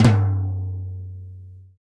Index of /90_sSampleCDs/Roland L-CDX-01/TOM_Rolls & FX/TOM_Tom Rolls
TOM MIDTOM0C.wav